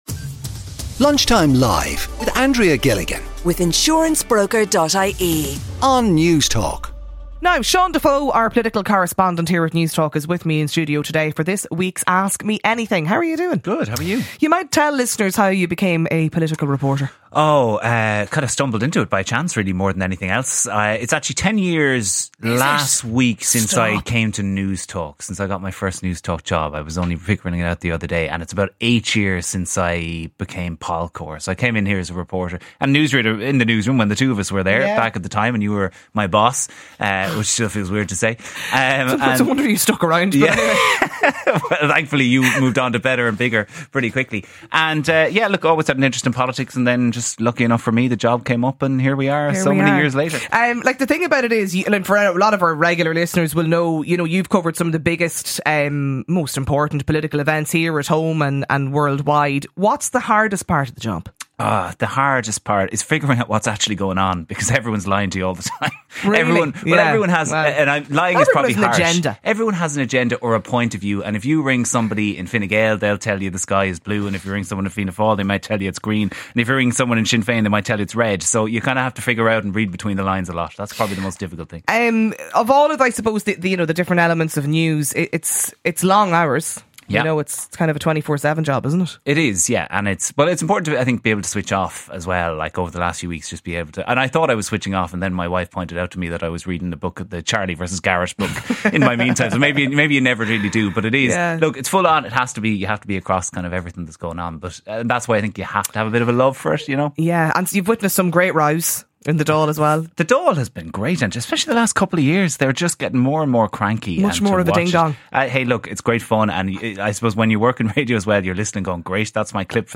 CONVERSATION THAT COUNTS | Ireland’s national independent talk station for news, sport, analysis and entertainment